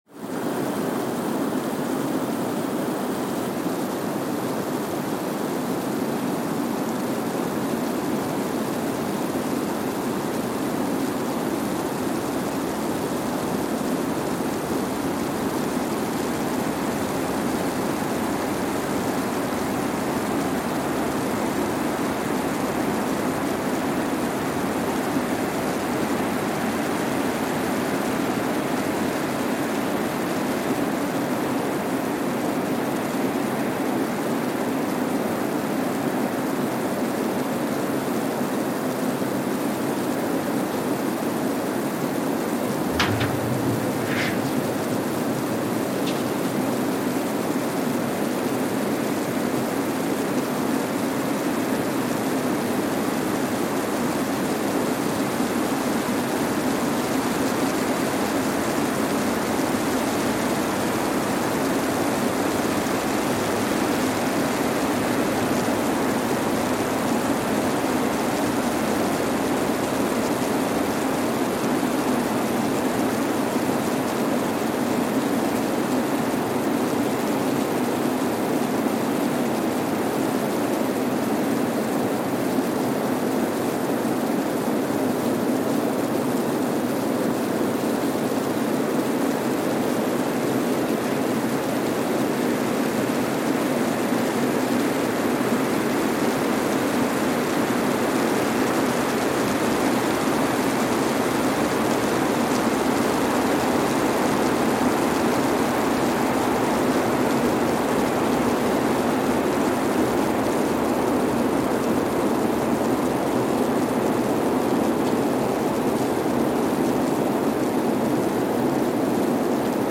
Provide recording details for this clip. Kwajalein Atoll, Marshall Islands (seismic) archived on September 20, 2023 Sensor : Streckeisen STS-5A Seismometer Speedup : ×1,000 (transposed up about 10 octaves) Loop duration (audio) : 05:45 (stereo) Gain correction : 25dB